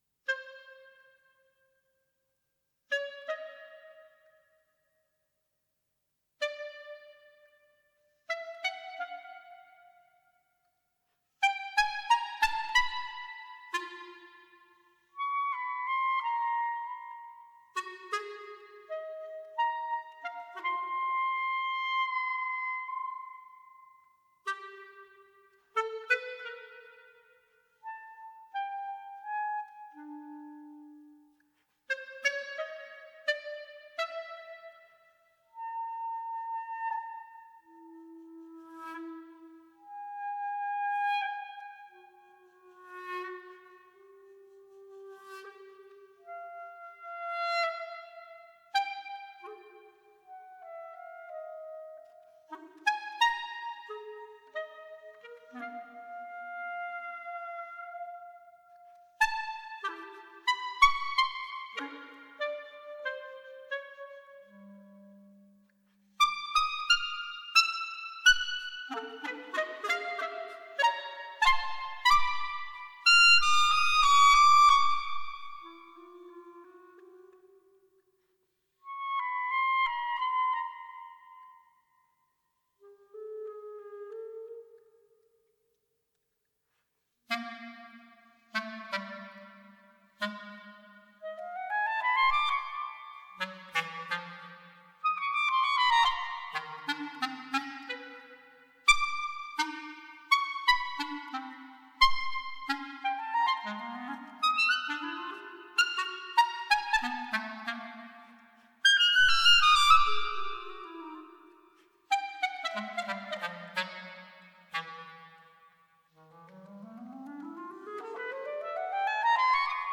II Фуга Камерно-инструментальная музыка 10.55 MB